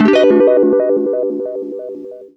GUITARFX13-R.wav